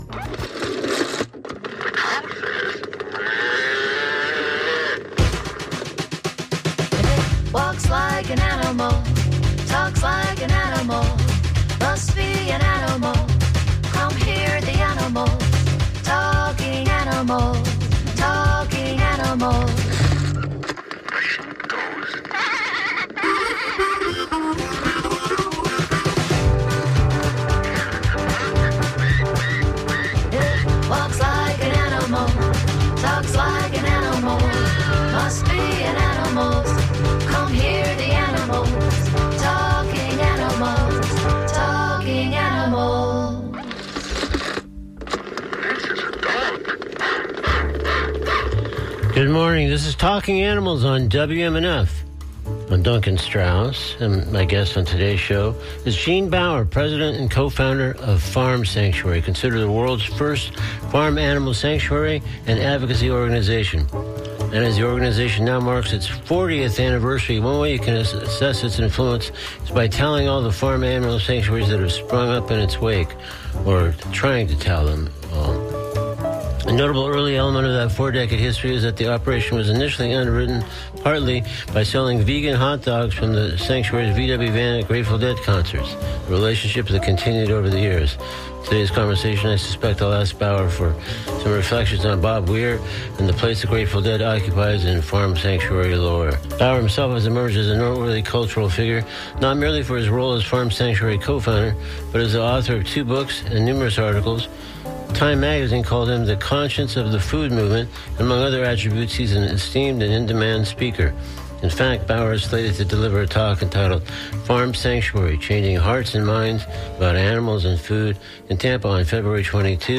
But the real reason we’d gathered on the radio was to discuss Raptor Fest–slated to happen Feb. 7, it’s the Preserve’s biggest event, an annual, all-day (10 a,m. to 4 p.m.) extravaganza.